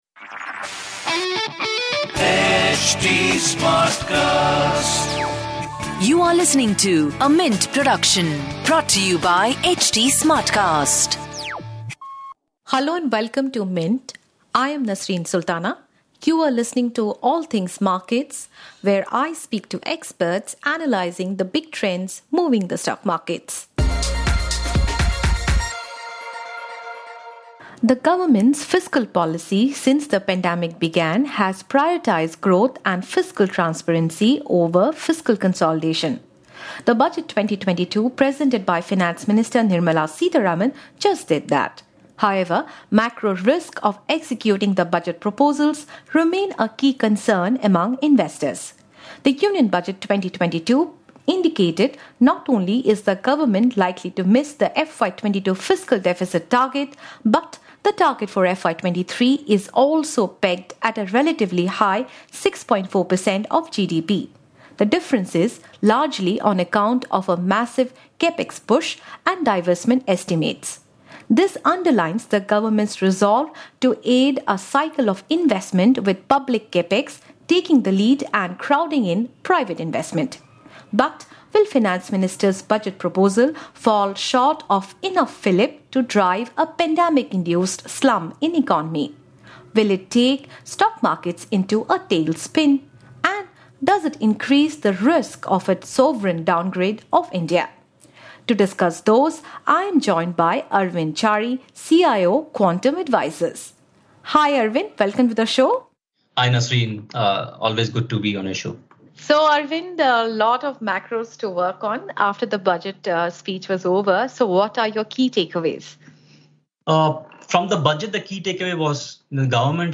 This weekly show covers an in-depth conversation with observers, analysts and experts decoding the movements in the stock markets.